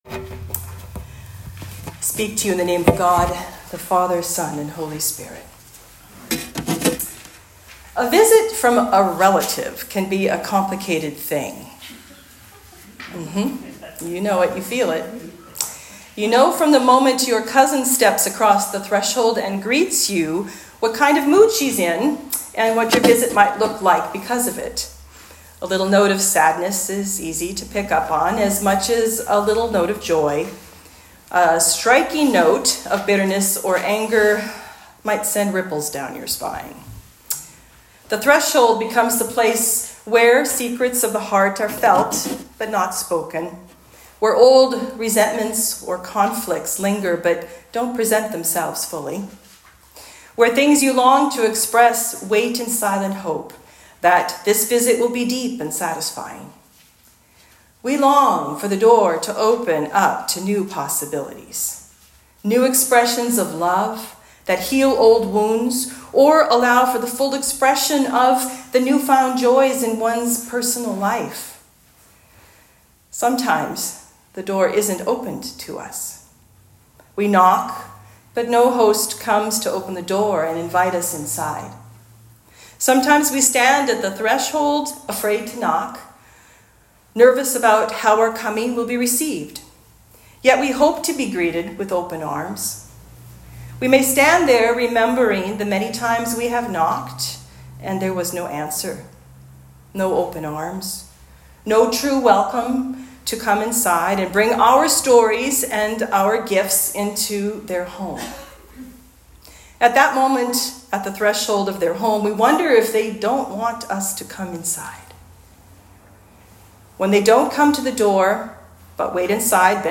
Sermons | Holy Trinity North Saanich Anglican Church
Advent 4 Talk